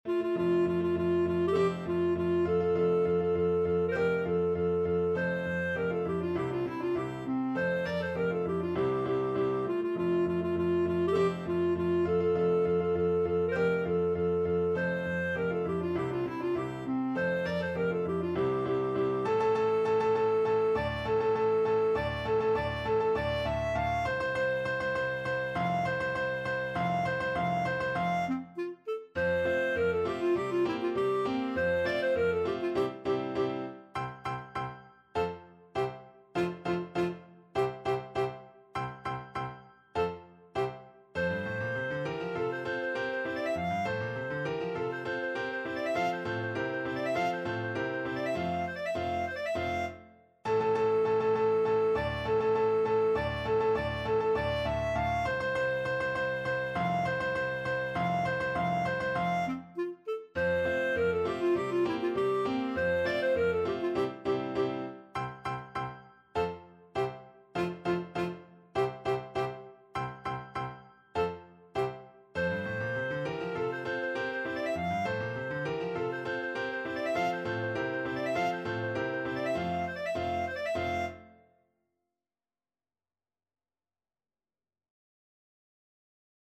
Free Sheet music for Clarinet
Clarinet
F major (Sounding Pitch) G major (Clarinet in Bb) (View more F major Music for Clarinet )
2/2 (View more 2/2 Music)
March = c.100
C5-F6
Classical (View more Classical Clarinet Music)
yorckscher_marsch_CL.mp3